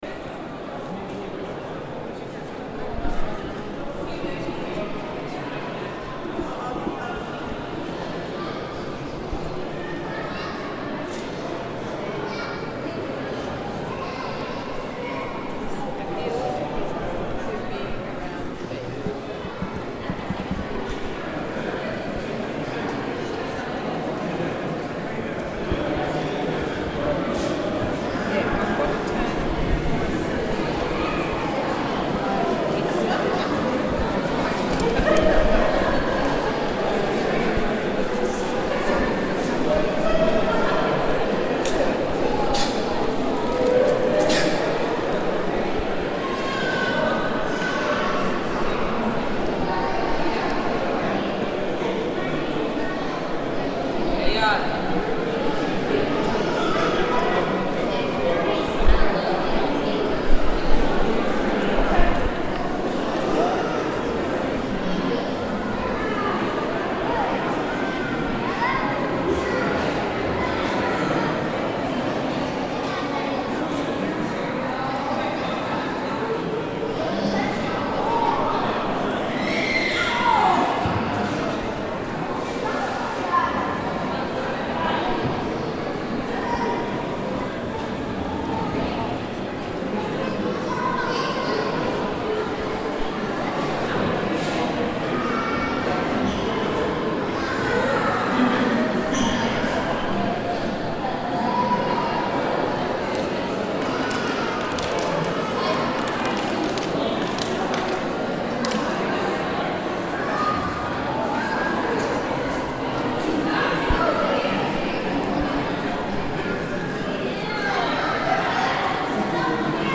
French Colonial incorporates live plants, street photography from Paris, France and field recordings from the American Museum of Natural History into an installation about human’s relationship to plants and biology as decor.
natural-history-museum.mp3